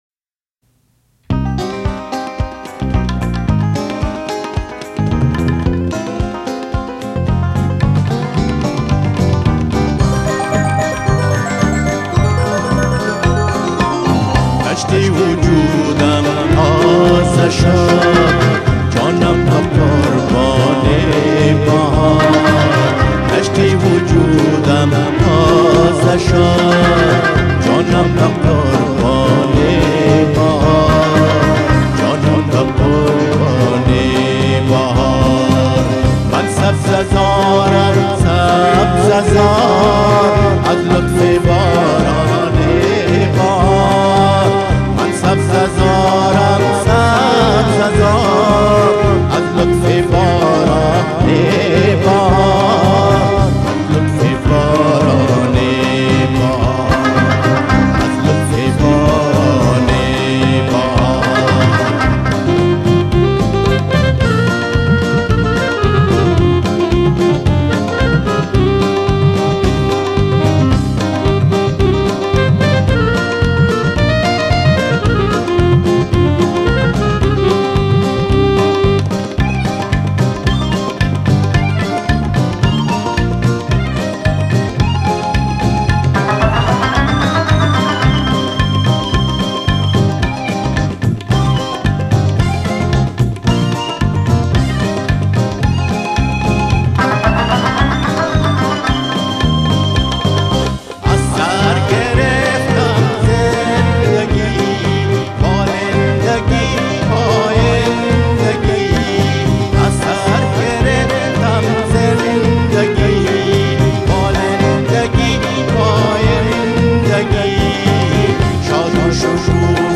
آوازی دارد چونان رود عصیانگر